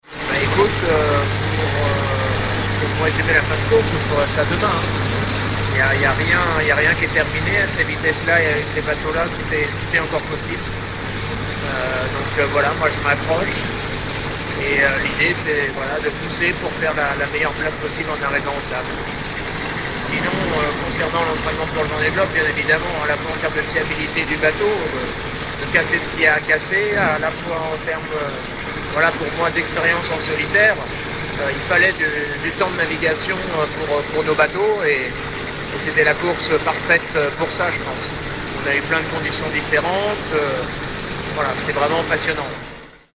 Joints à 5h ce mardi 14 juillet, les skippers nous racontent les conditions dans lesquelles ils naviguent sur ce tout dernier tronçon avant la ligne d'arrivée devant Les Sables d'Olonne...